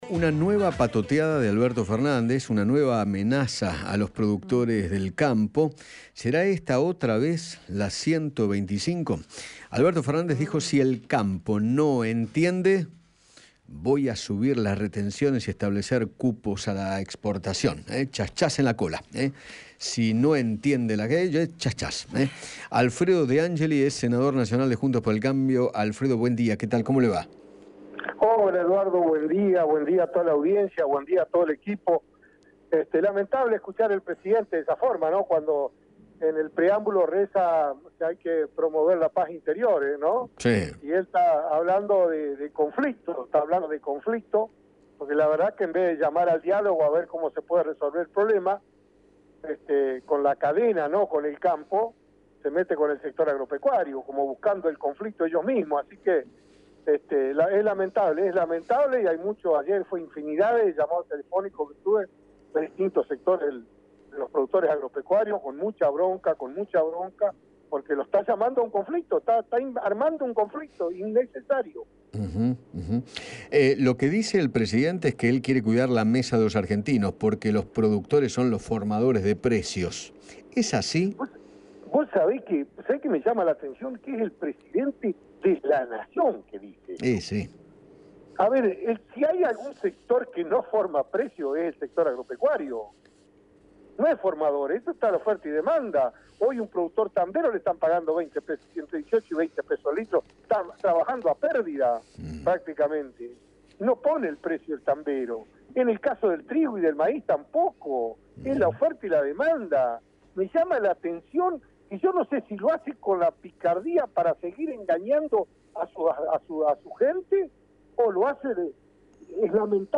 El senador Alfredo De Ángeli dialogó con Eduardo Feinmann sobre las declaraciones del presidente, quien advirtió una posible suba a las retenciones o establecer cupos a la exportación si el campo no entiende.